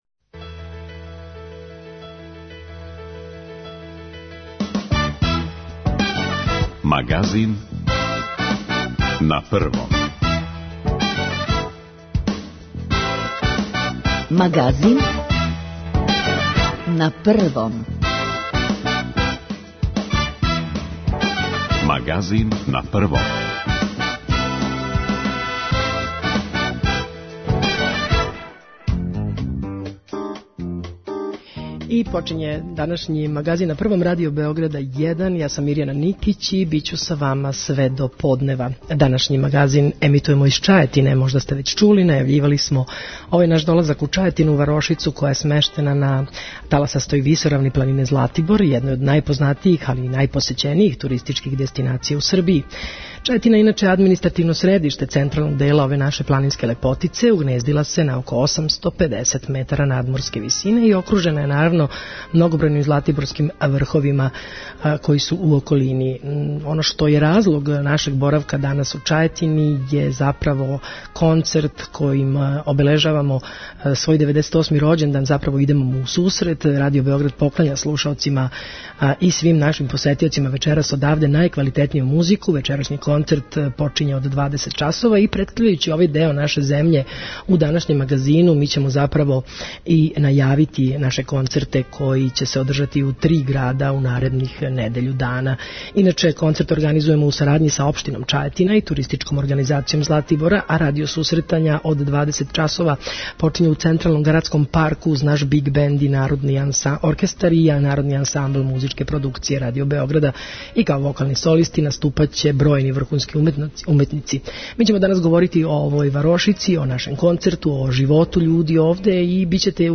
Данашњи Магазин емитујемо из Чајетине, варошице која је смештена на таласастој висоравни планине Златибор, једној од најпознатијих и најпосећенијих туристичких дестинација у Србији.